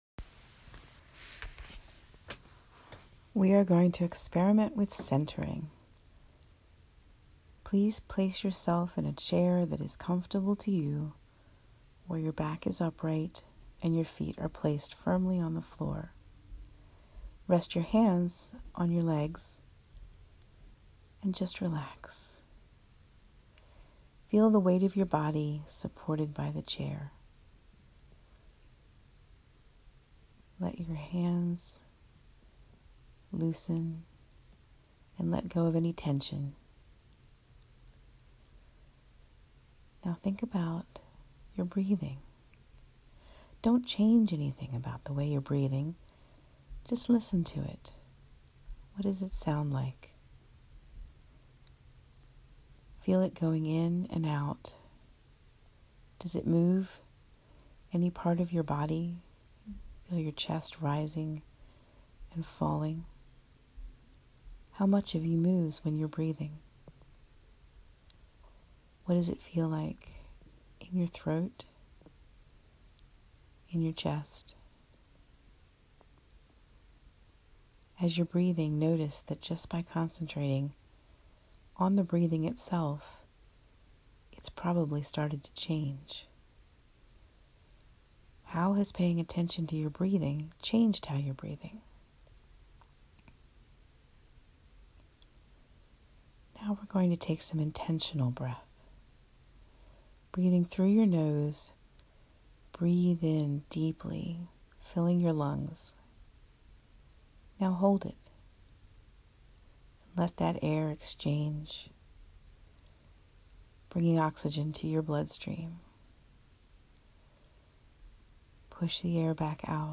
You may enjoy this three and a half minute breathing exercise which provides another way to explore centering through breathing.